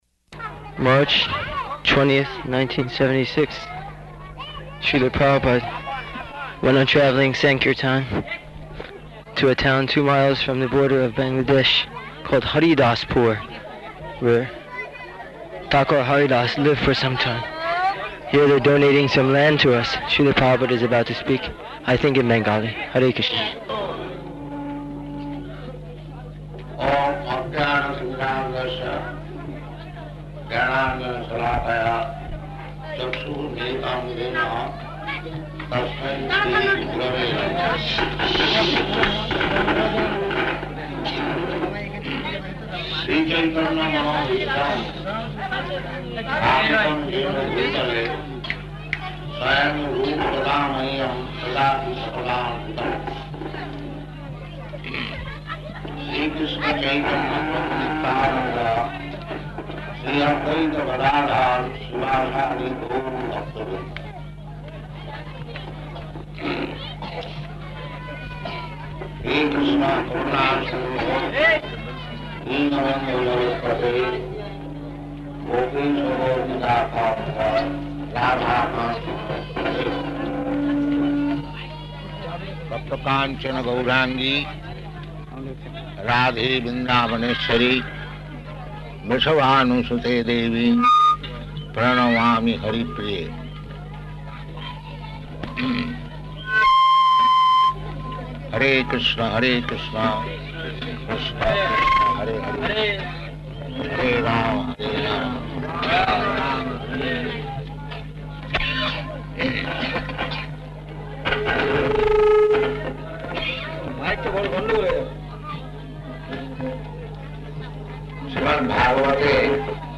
Lecture in Bengali
Lecture in Bengali --:-- --:-- Type: Lectures and Addresses Dated: March 20th 1976 Location: Haridaspur Audio file: 760320LE.HAR.mp3 Devotee: [announcing] March 20th, 1976.